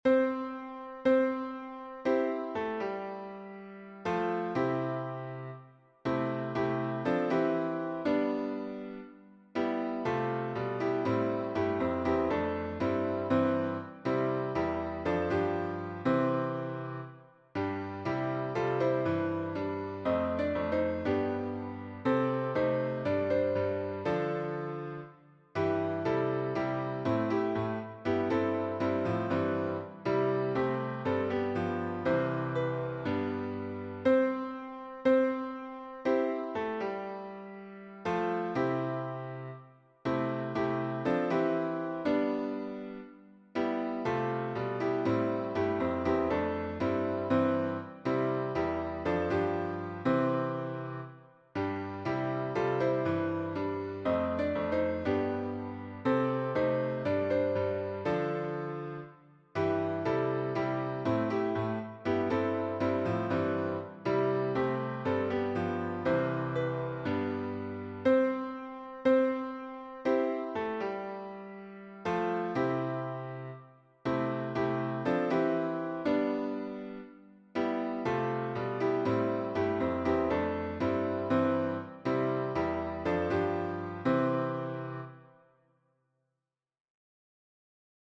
BlessYouSATB(pianosynth).mp3